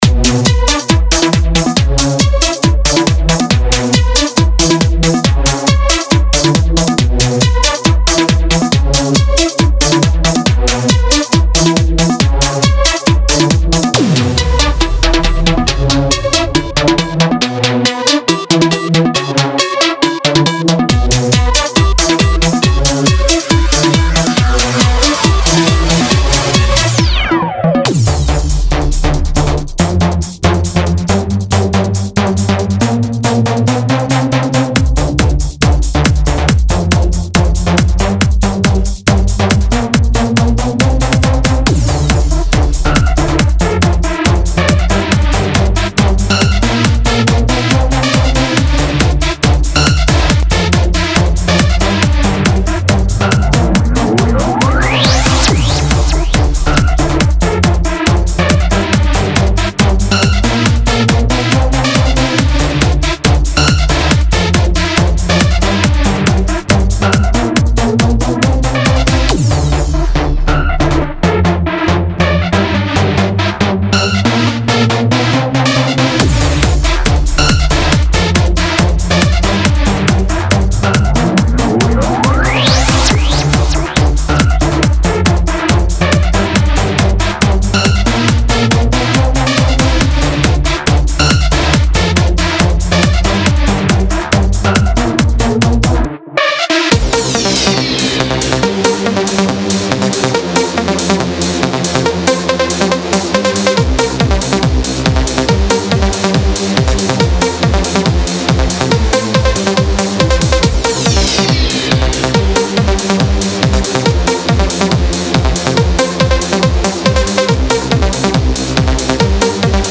ACID LOOPS LIBRARY
address latest trends in Trance
No Fluff, No Fillers just pure Trance Energy.